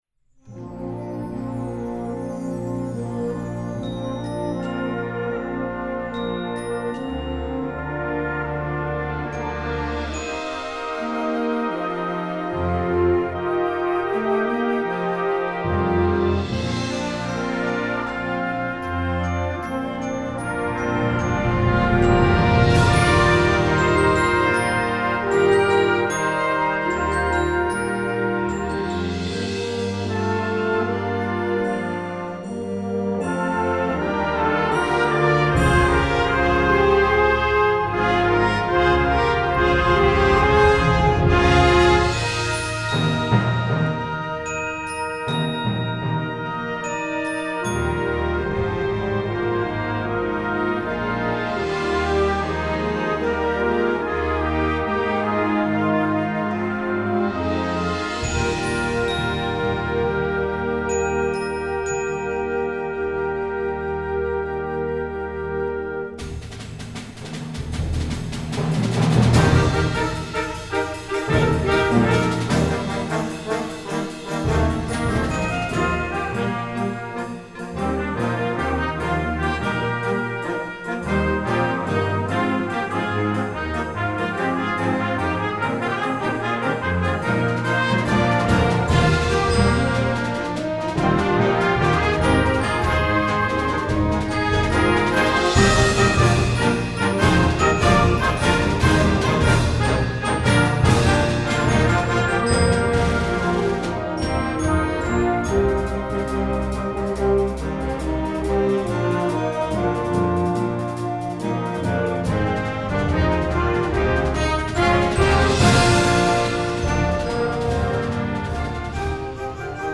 Voicing: Concert Band